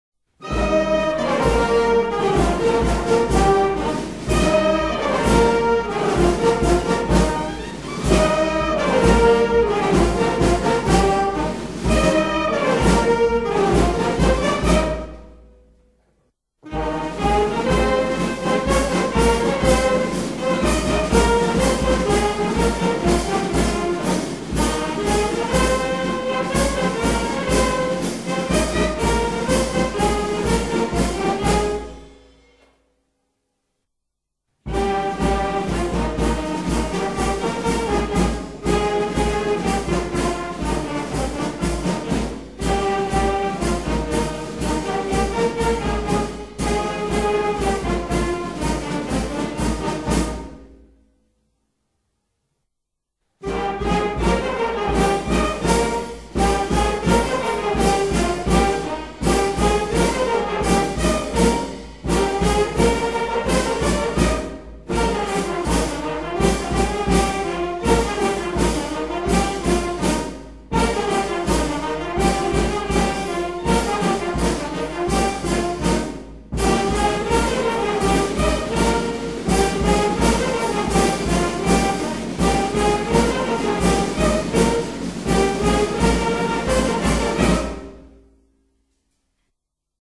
ritournelles particulières exécutées pour illustrer les phase d'une joute